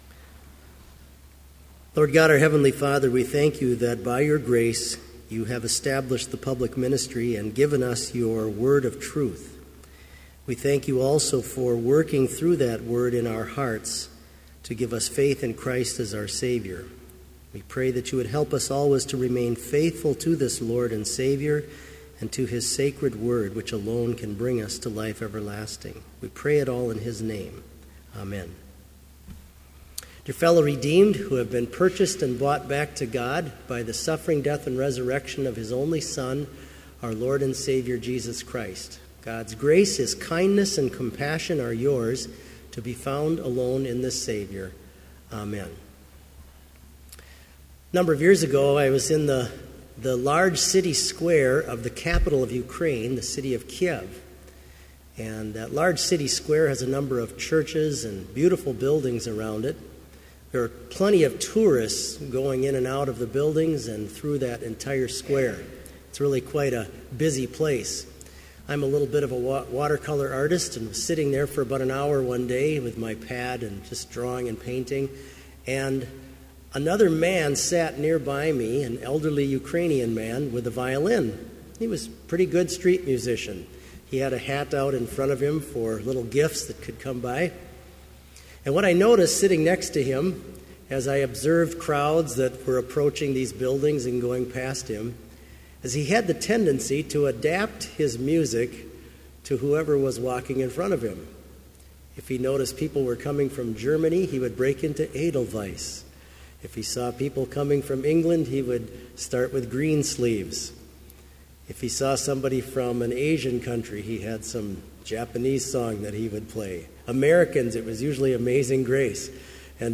Sermon audio for Evening Vespers - February 11, 2015